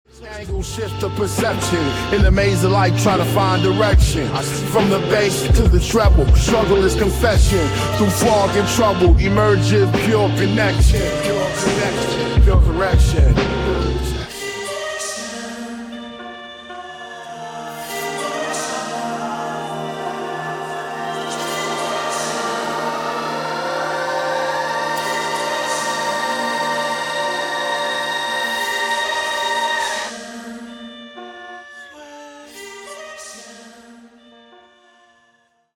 An incredible Hip Hop song, creative and inspiring.